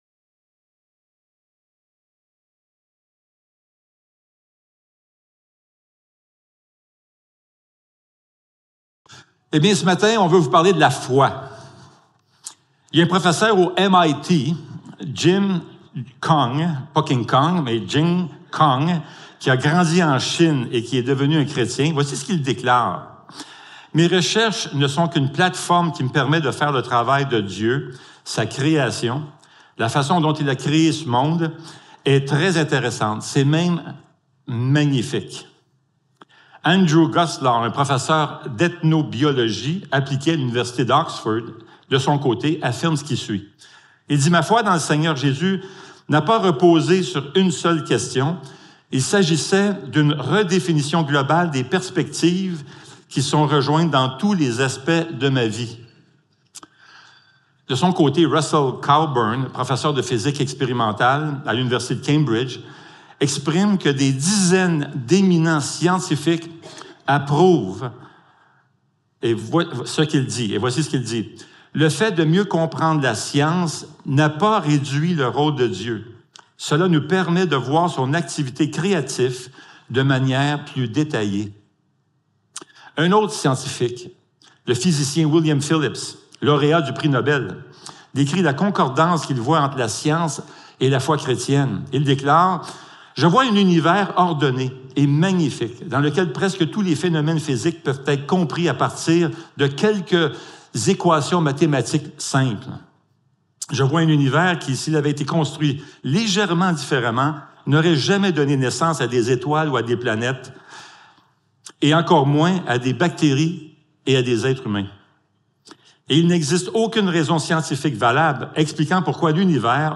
Jacques 2.14-26 Service Type: Célébration dimanche matin Vers la maturité spirituelle #5 La foi est l'un des thèmes les plus importants de la Bible.